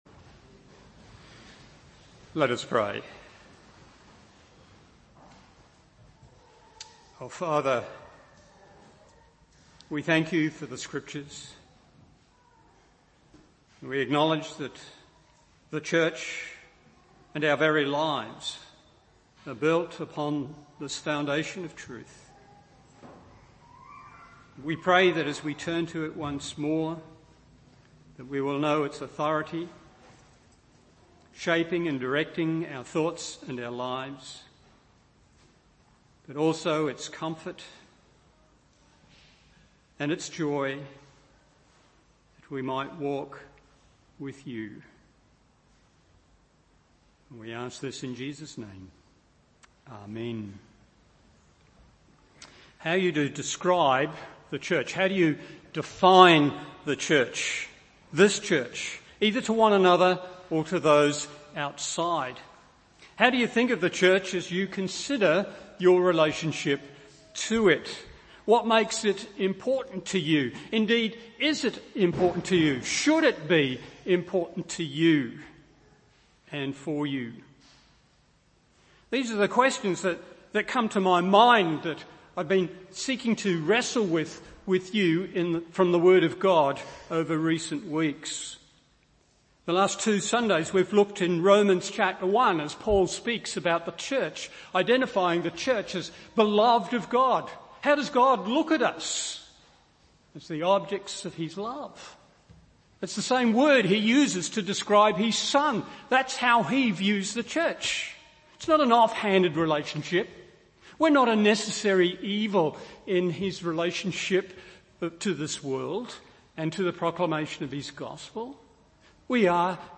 Morning Service